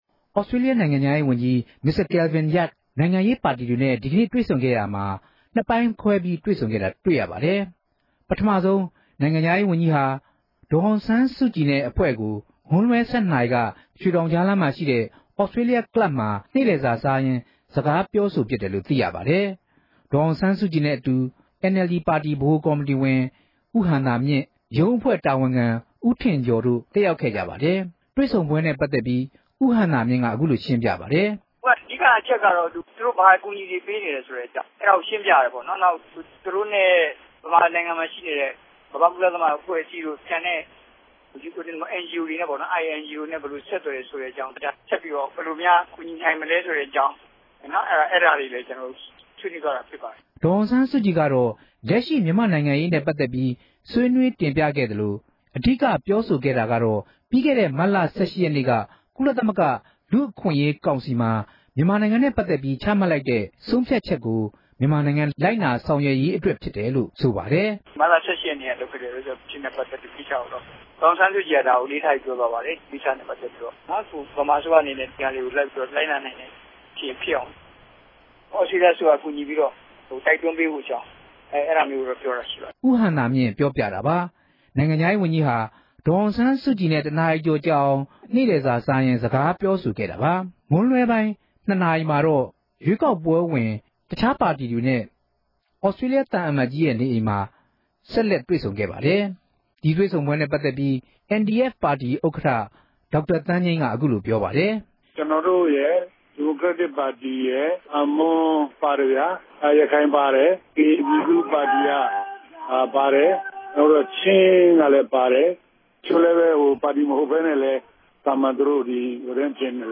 စုစည်းတင်ပြချက်။